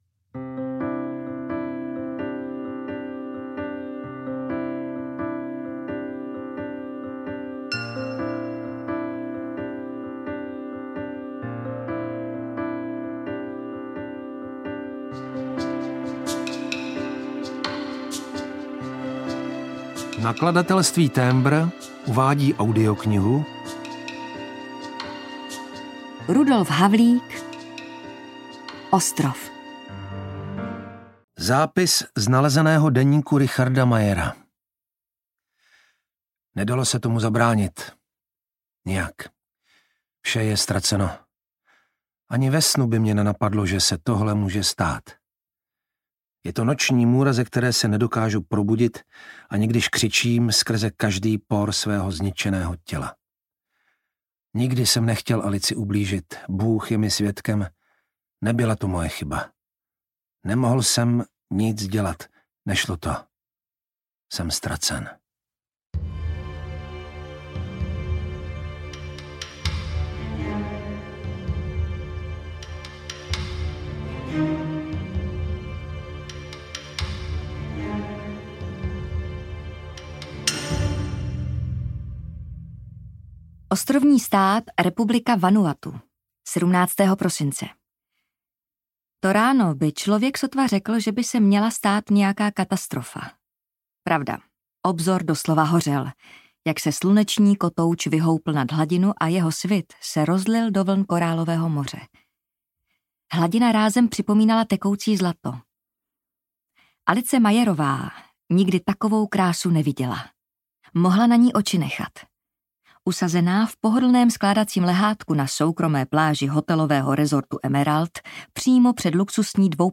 Ostrov audiokniha
Ukázka z knihy
• InterpretJana Plodková, Jiří Langmajer